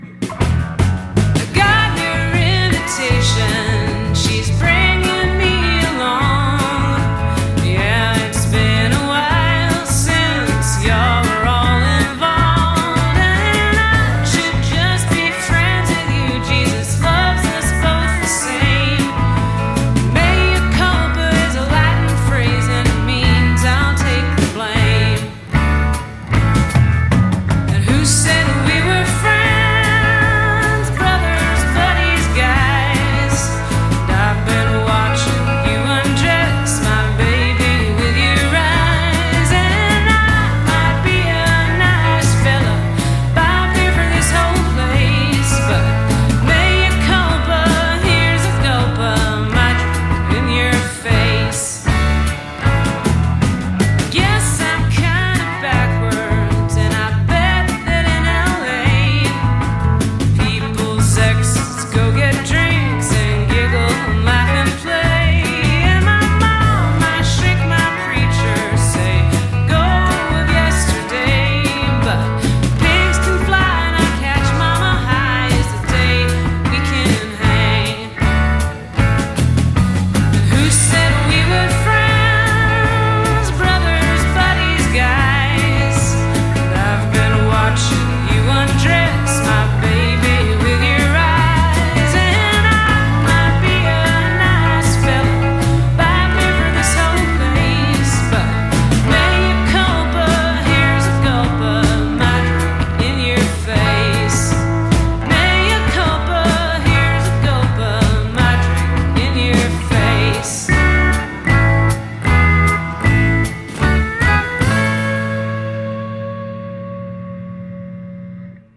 BPM155
Audio QualityMusic Cut
CommentsHow 'bout some alt-country for y'all?